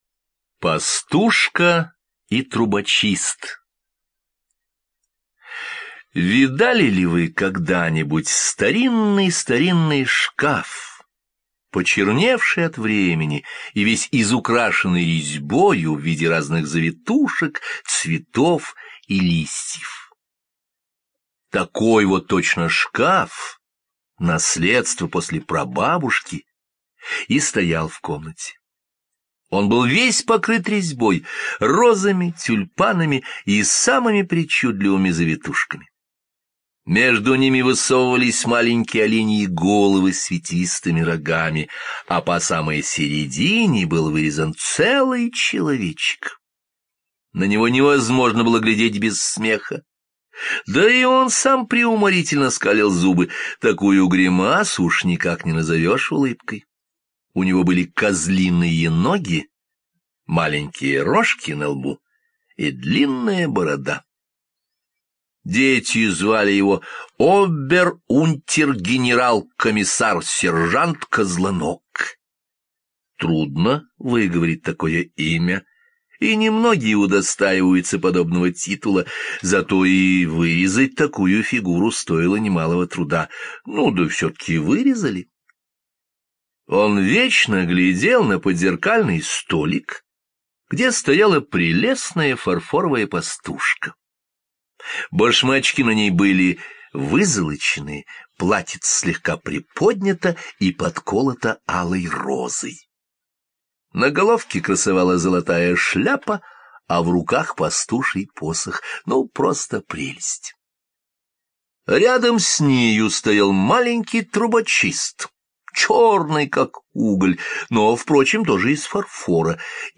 Пастушка и трубочист — аудиокнига Андерсена, которую вы можете слушать онлайн или скачать.
Пастушка-и-трубочист-мужской-голос.mp3